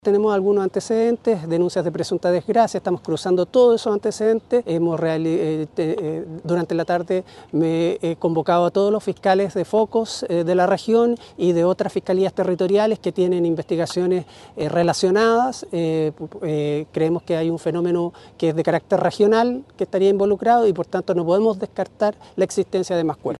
El fiscal regional Aquiles Cubillos no descarta encontrar más cuerpos en este operativo y habla de un fenómeno regional en este caso, escuchemos: